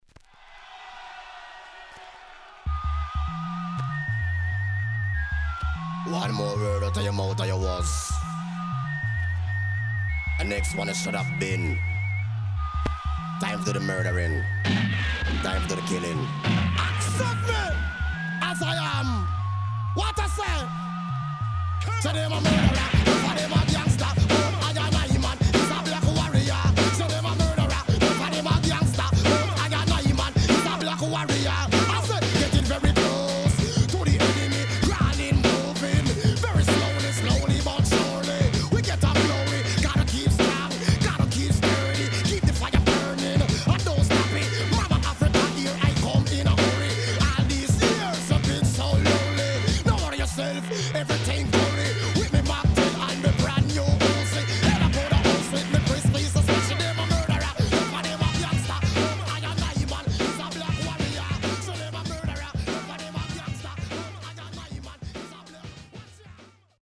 HOME > Back Order [DANCEHALL LP]
SIDE A:出だし傷によるノイズありますが良好です。